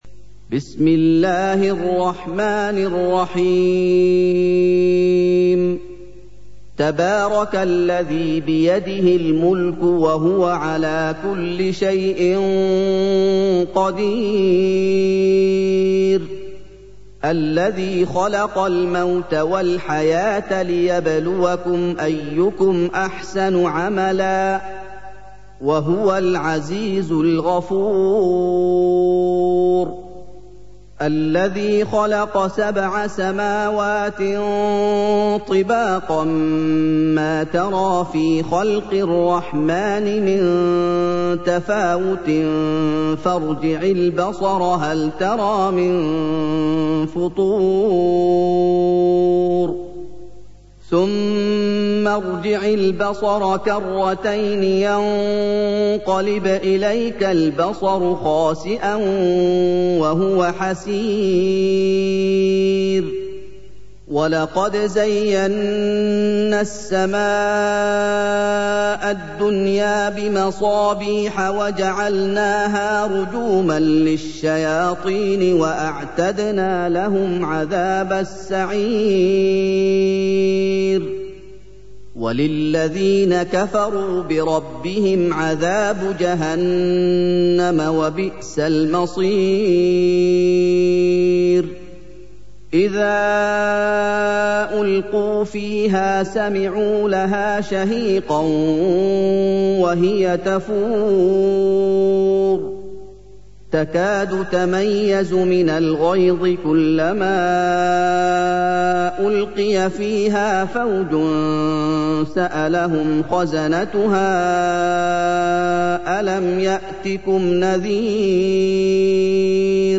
سُورَةُ المُلۡكِ بصوت الشيخ محمد ايوب